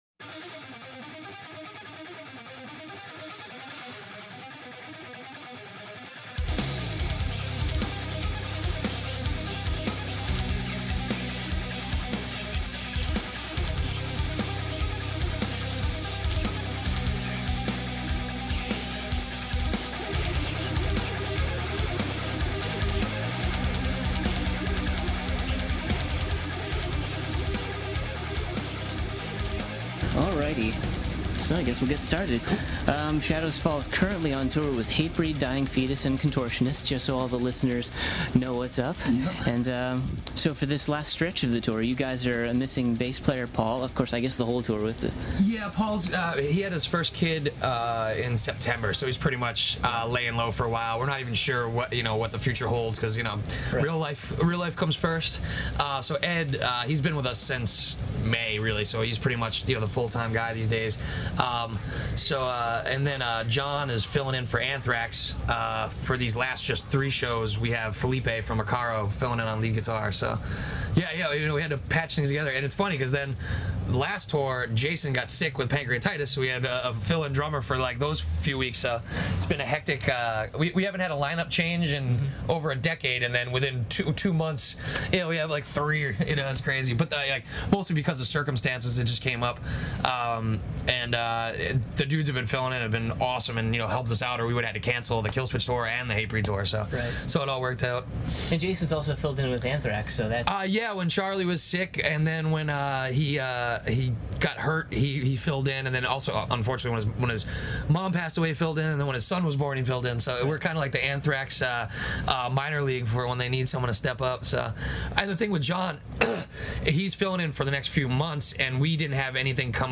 Exclusive: Shadows Fall Interview
Sitting in the back of the tour bus before the band took the stage at Allentown’s Crocodile Rock Cafe, the two elaborated upon the several missing members on the tour and the ones filling in, the recording of their seventh studio album Fire From the Sky (Razor & Tie) and working with mega producer Adam D (Killswitch Engage), signing with Razor & Tie and the stability of the current state of the met
interview-shadows-fall.mp3